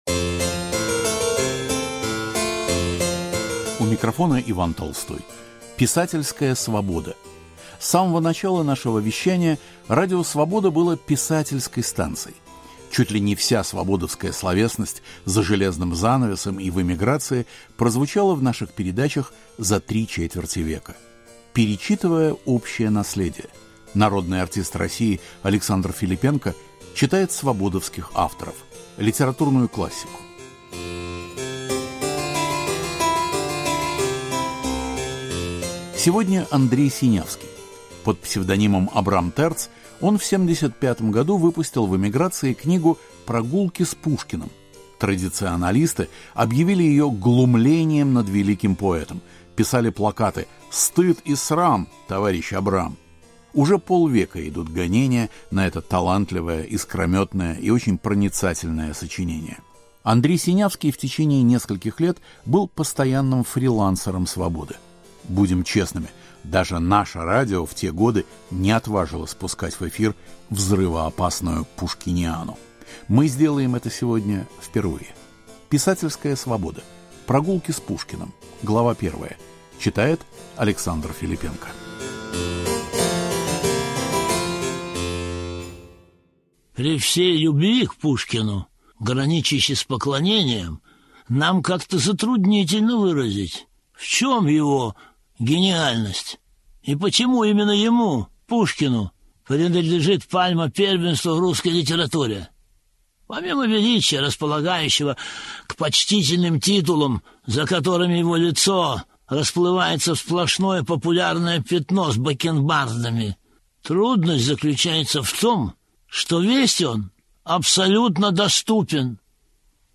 Александр Филиппенко читает фрагмент из книги Абрама Терца "Прогулки с Пушкиным"